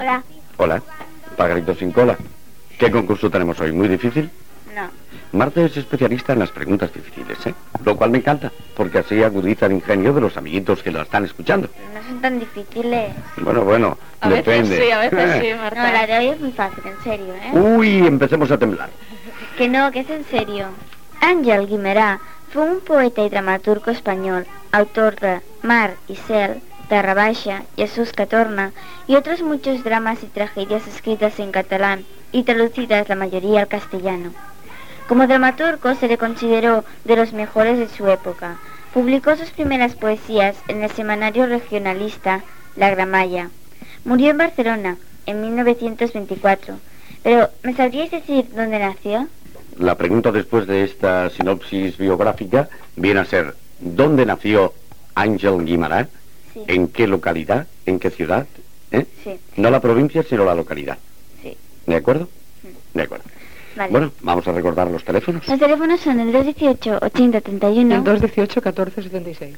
Telèfons, pregunta sobre Girona i trucades de l'audiència.
Infantil-juvenil